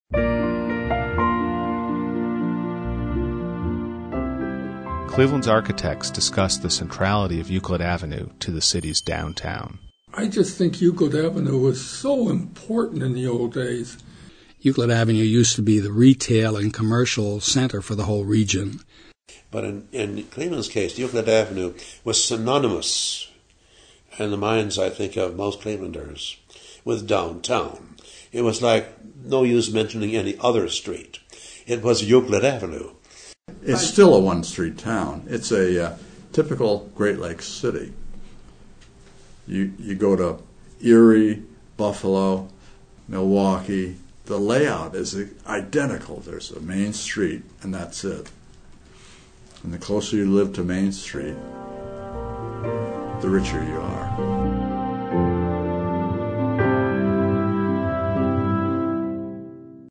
Cleveland's architects discuss the centrality of Euclid Avenue to the city's downtown | Source: Cleveland Regional Oral History Collection
Cleveland Regional Oral History Collection